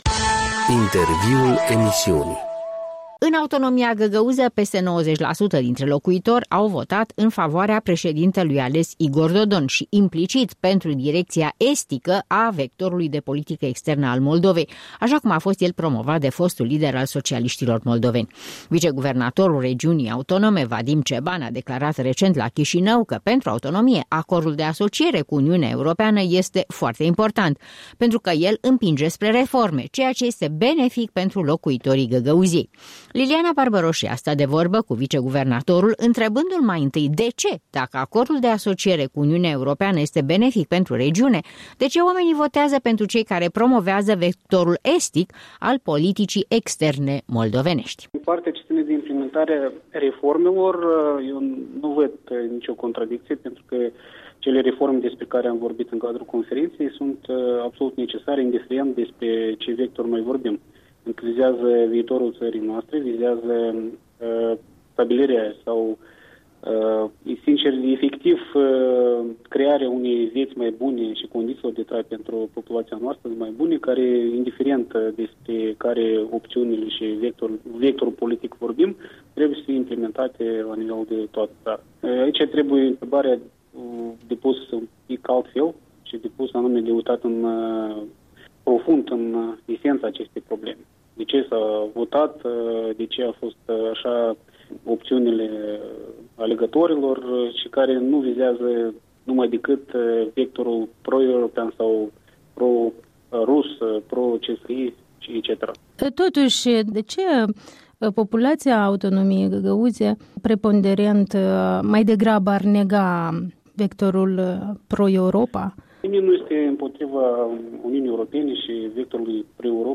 Un interviu cu vice-președintele regiunii autonome găgăuze.